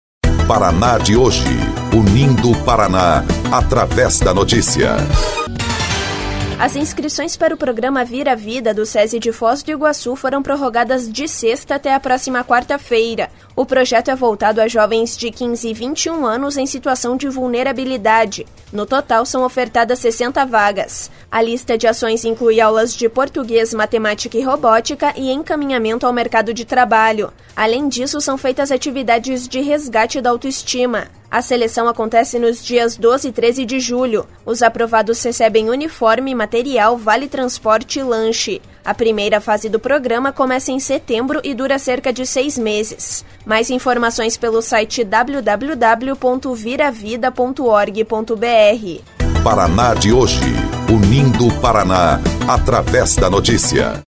BOLETIM – Programa ViraVida prorroga inscrições em Foz do Iguaçu